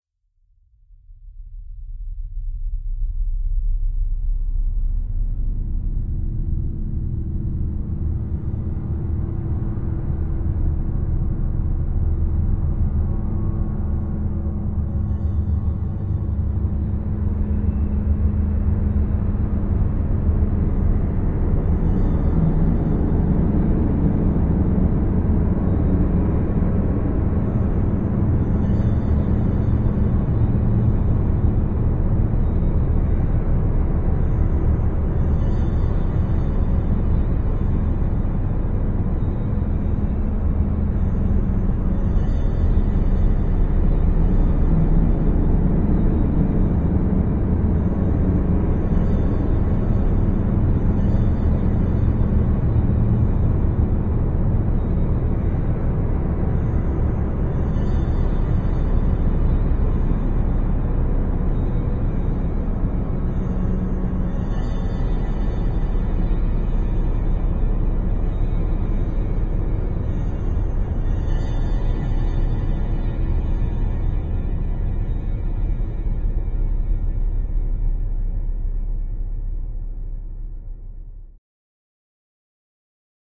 Huge Sub Space Expanse Space, Sub, Huge